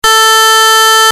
ユニークな波形がＶＣＯ２から出力されるわけです（図−２）。
２ＶＣＯ間のピッチのズレによって音色は決まると言いましたが、これをＥＧで大幅に変えればハードな電気ギターにも負けないような音色を実現できるというわけです。